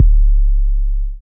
OG808_YC.wav